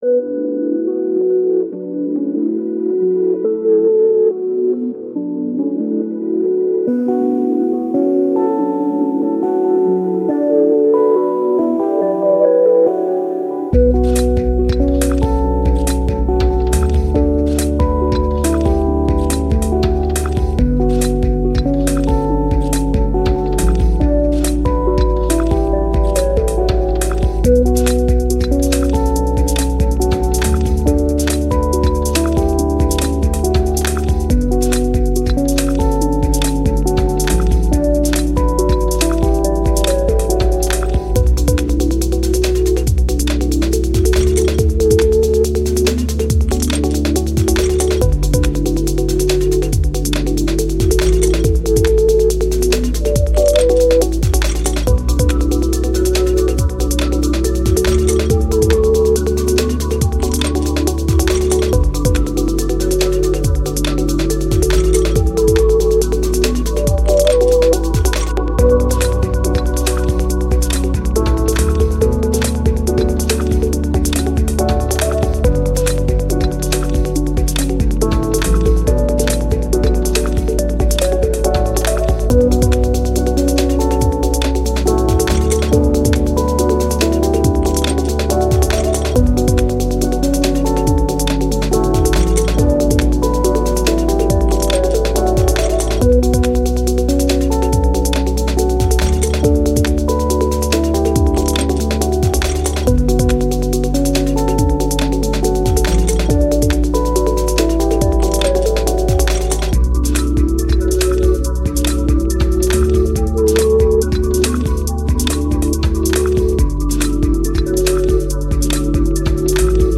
Ambient, Electronic, Glitch, IDM, Reverse, Landscapes, Night